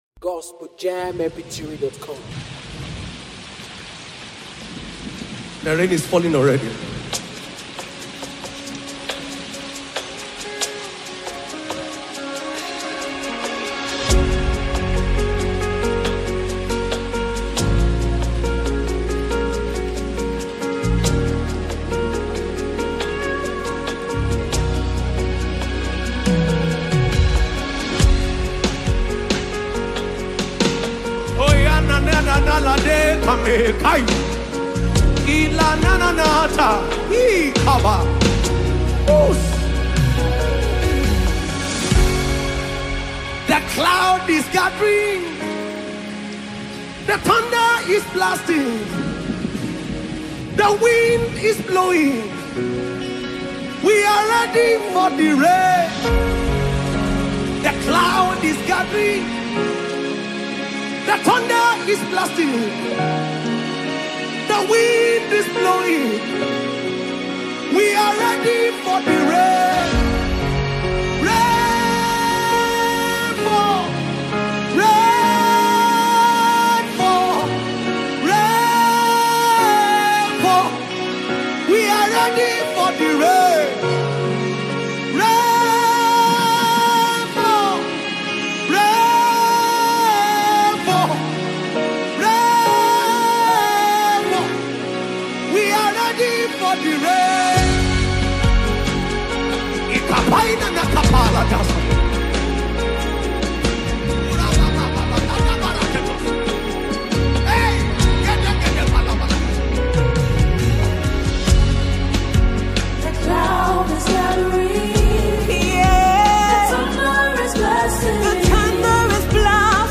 Fast-rising Nigerian Gospel Singer